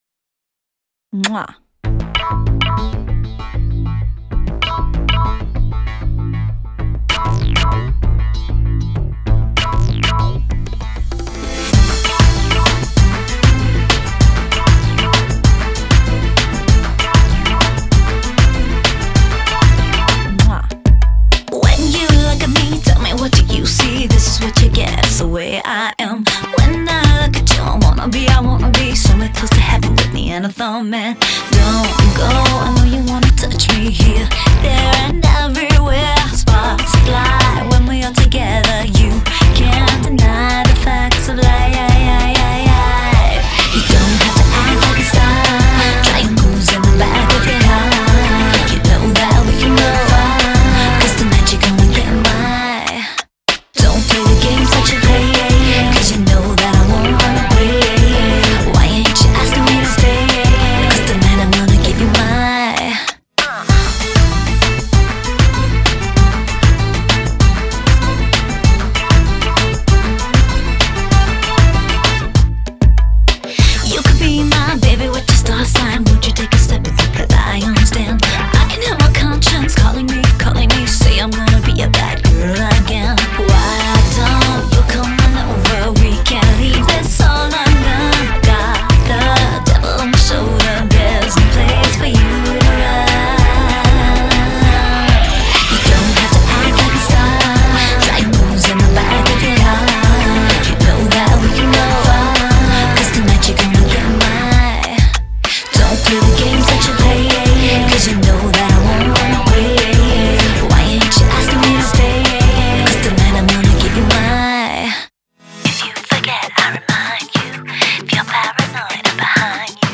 结合欧陆和中东的异国音乐